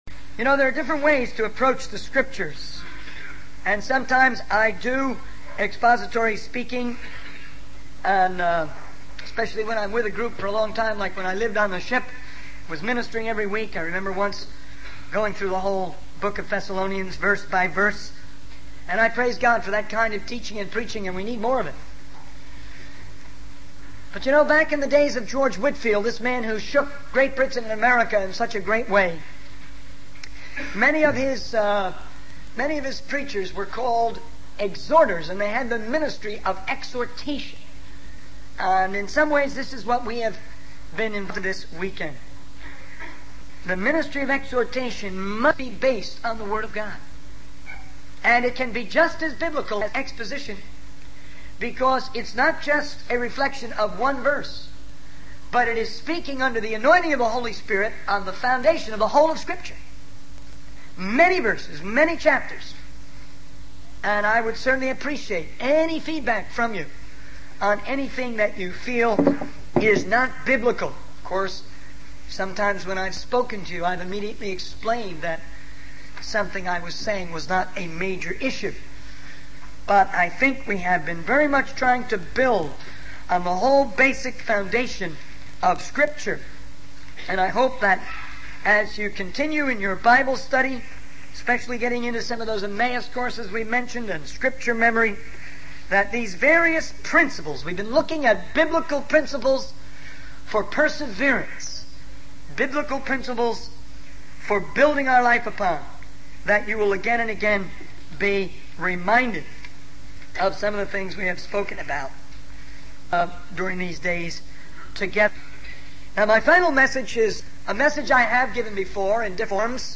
In this sermon, the speaker discusses various principles and teachings related to the Christian faith. He emphasizes the importance of being loving and careful in our actions and words towards others. The speaker also talks about the need to stay focused on Jesus and not neglect our families.